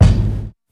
Kick (Cowboy).wav